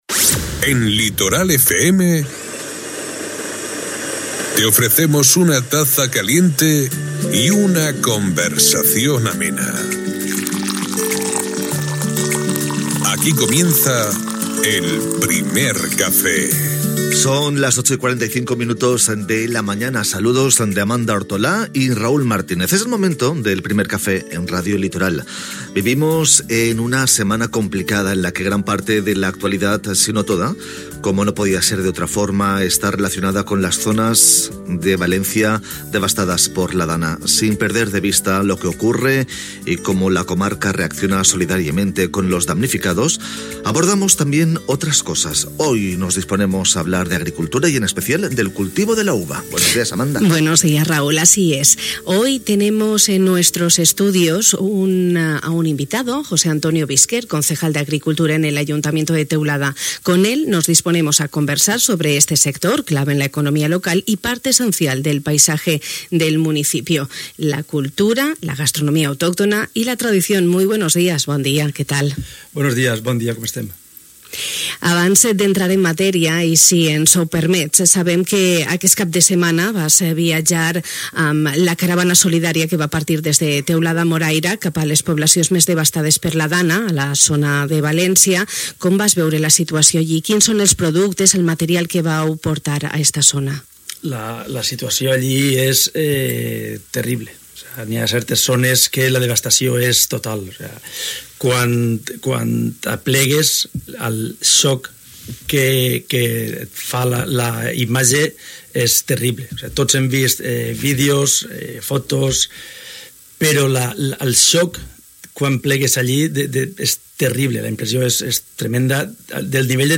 José Antonio Bisquert, regidor d’Agricultura a l’Ajuntament de Teulada ens ha acompanyat aquest matí al Primer Café de Radio Litoral. Amb ell hem conversat sobre el sector agrícola.